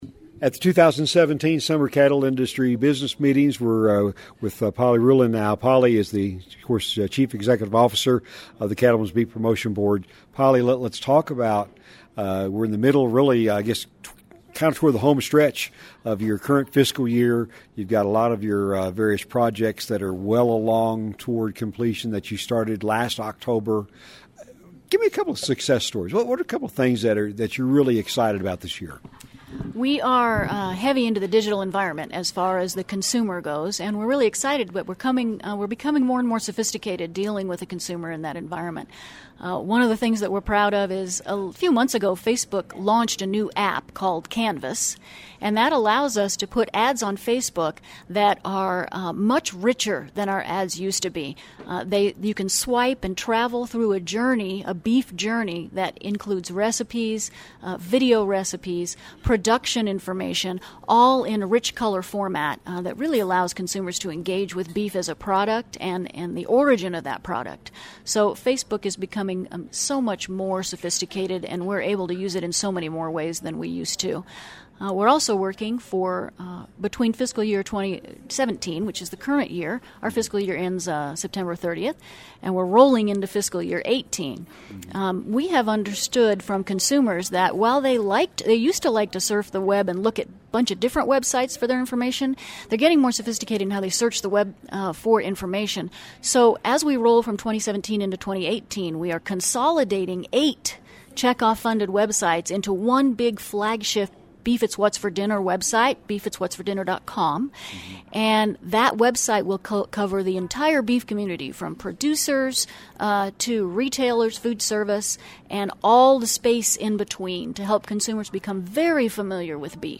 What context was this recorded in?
You can listen to their entire conversation, that took place during the National Cattlemen's Beef Association Summer Business Conference in Denver last week, by clicking or tapping the LISTEN BAR at the bottom of this story.